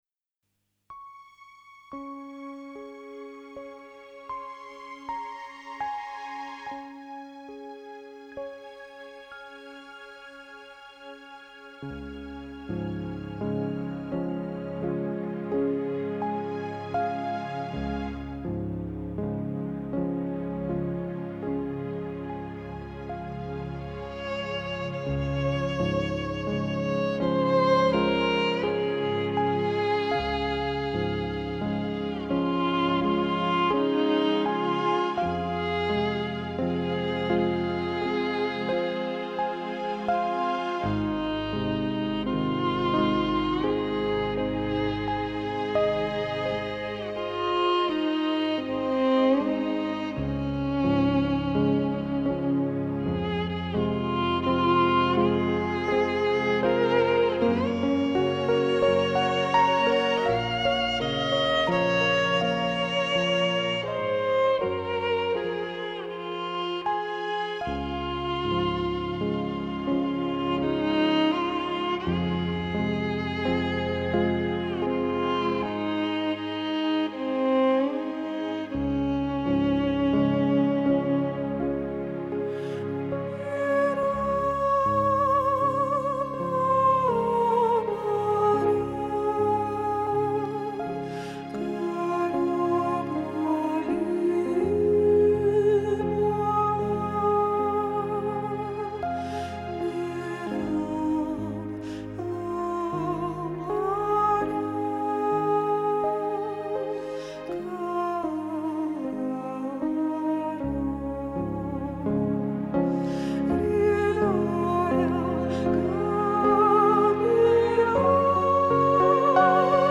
devotional and meditative songs
a violinist and keyboard player from the Ukraine